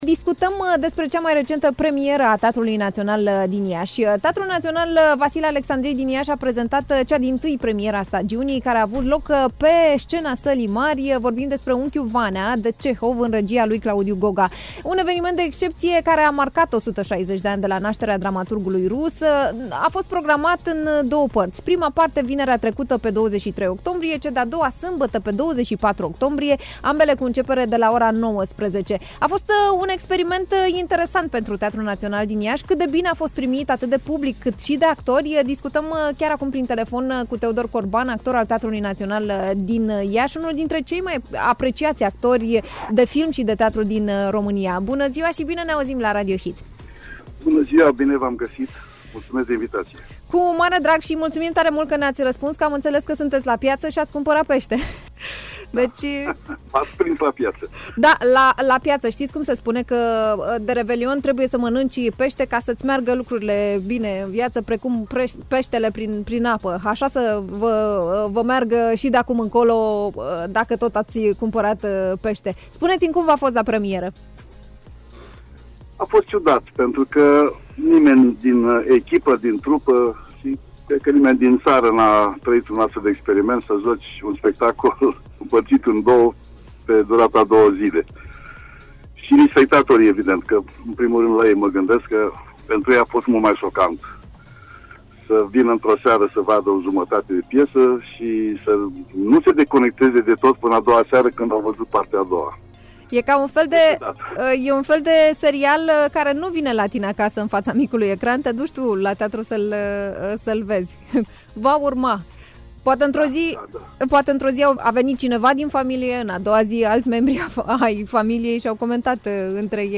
În Be the HIT, am stat de vorbă cu actorul Teodor Corban, despre cea mai recentă premieră a Teatrului Național din Iași.